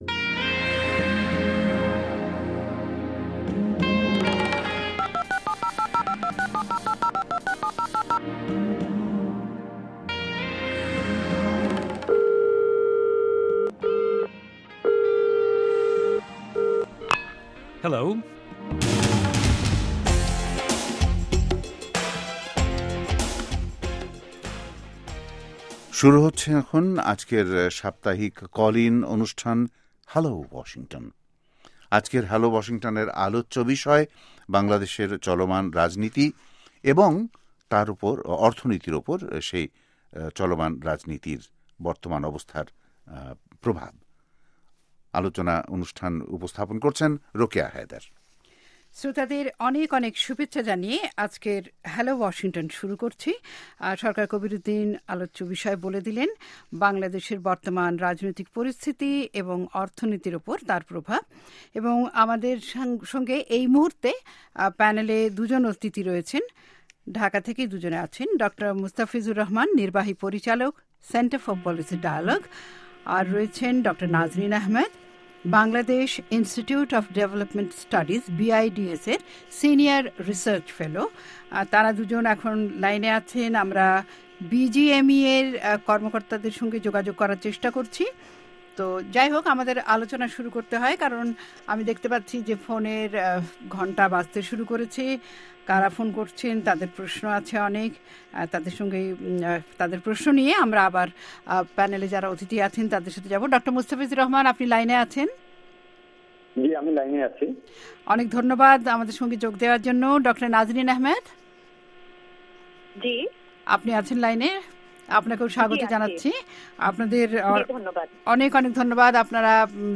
শুনুন কল ইন শো